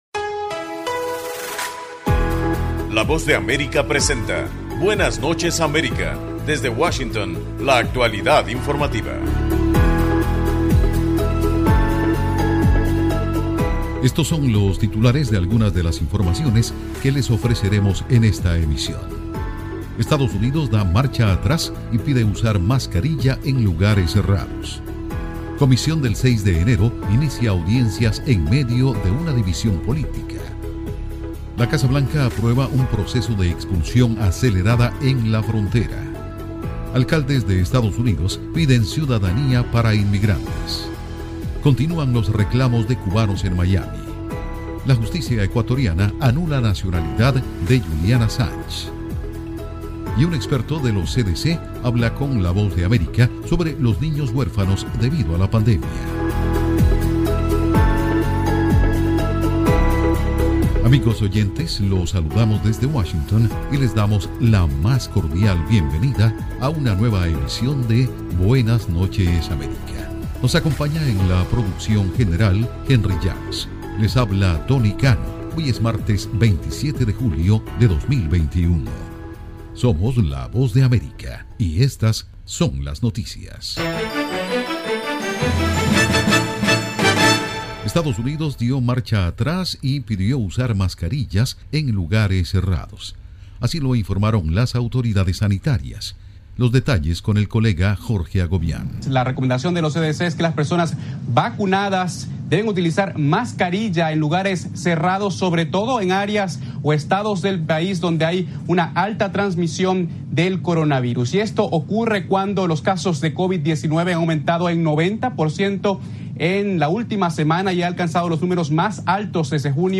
PROGRAMA INFORMATIVO DE LA VOZ DE AMERICA, BUENAS NOCHES AMERICA.